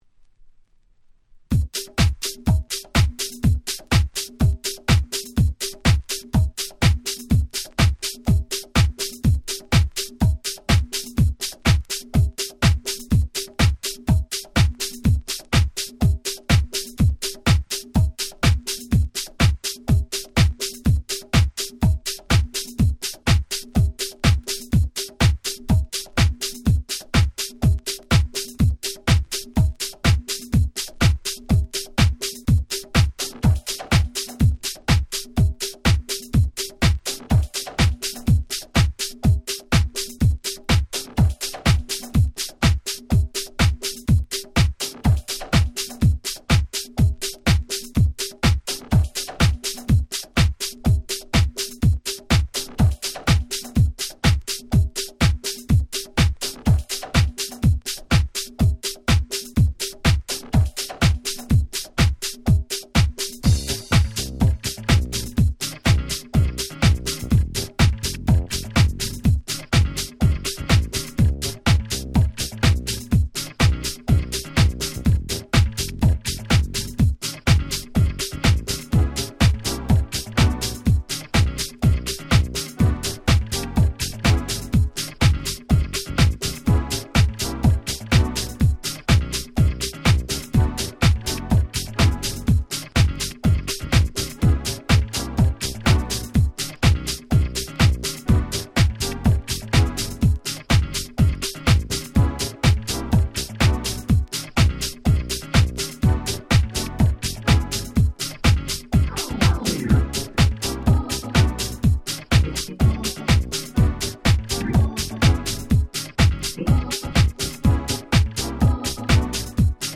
07' Very Nice Vocal House Remix !!
00's ハウス